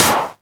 VEC3 Claps 059.wav